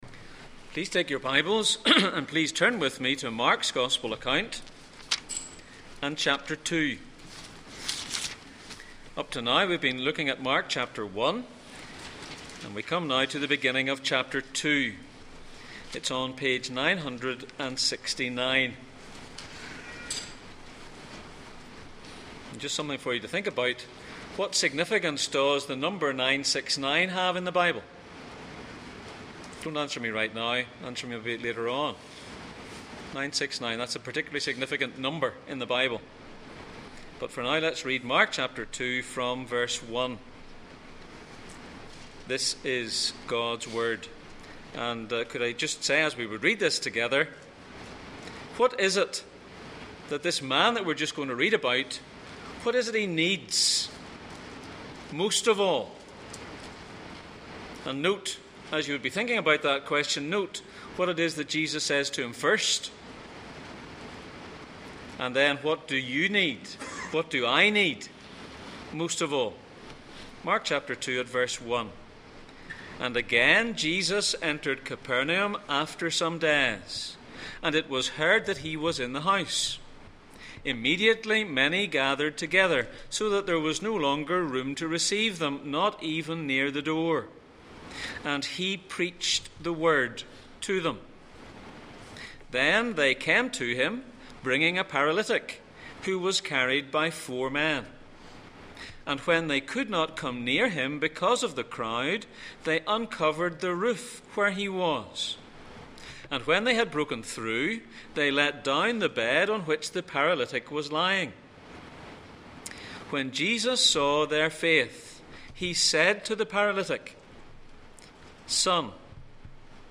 Passage: Mark 2:1-12, Luke 5:17, Mark 14:61-62 Service Type: Sunday Morning